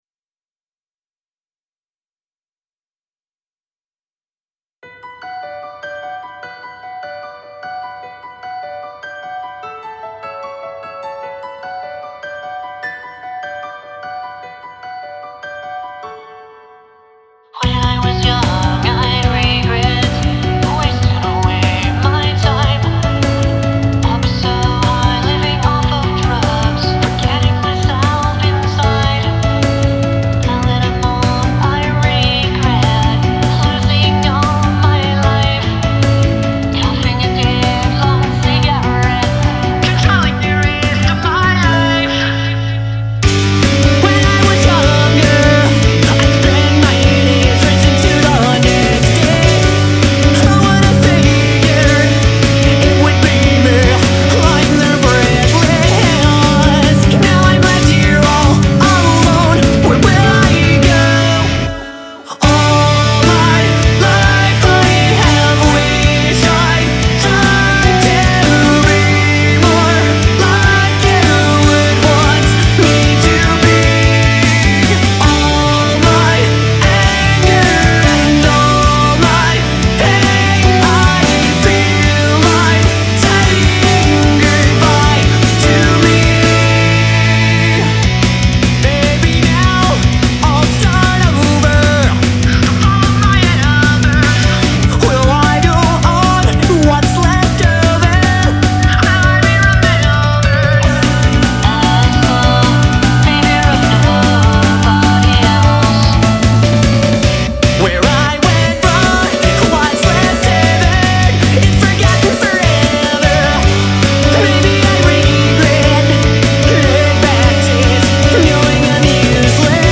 im having issues with eq problems on this mix still imo so if i could get some advice it would be much appreciated.